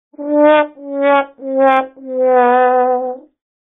Sad Trombone Sound Effect Free Download
Sad Trombone